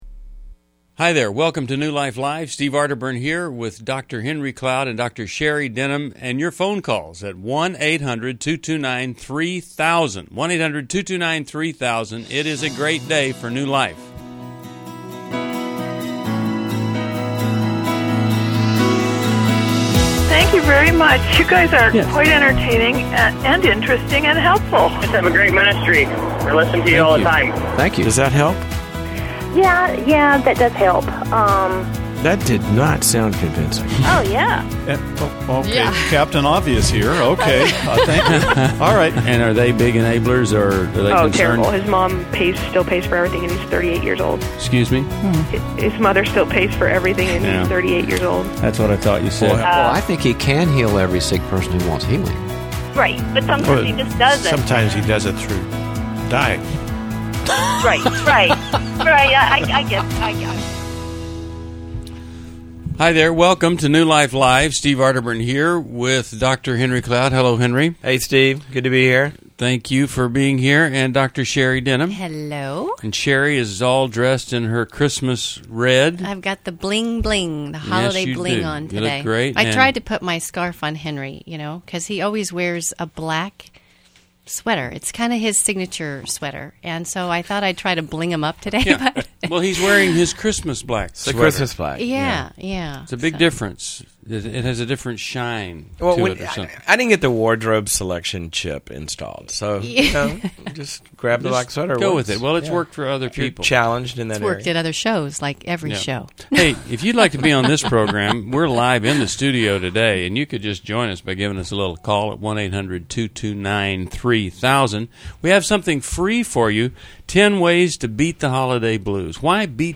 Caller Questions: Testimony from New Life supporter.